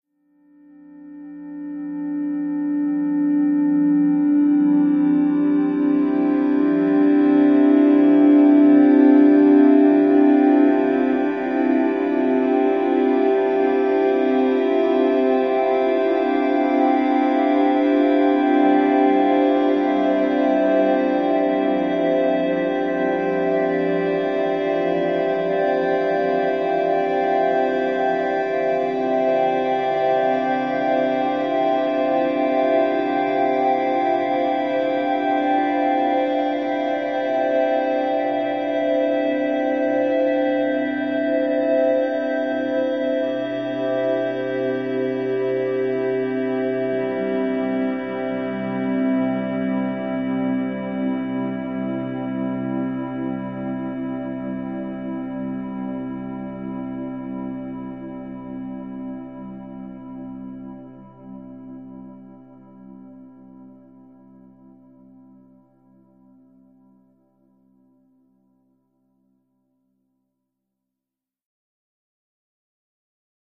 Angelic Long Rising Texture Angelic, Rising